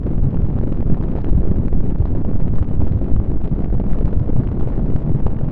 rocket_sound.wav